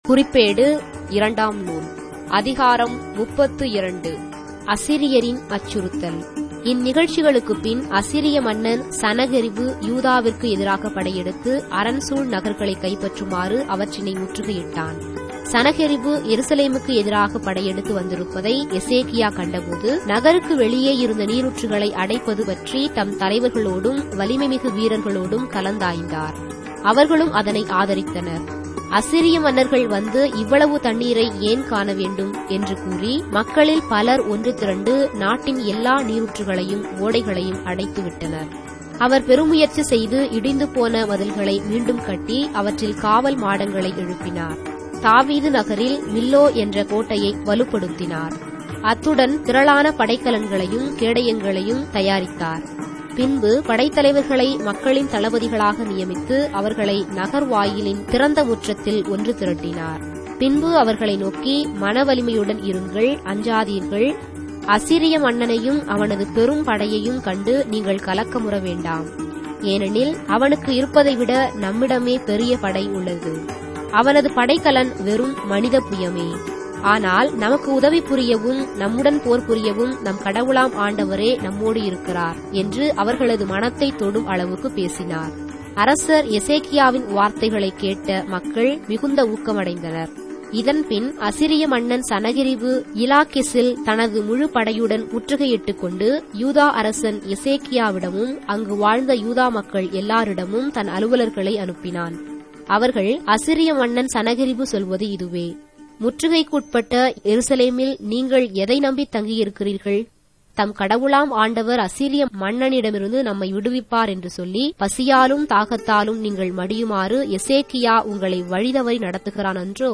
Tamil Audio Bible - 2-Chronicles 2 in Ecta bible version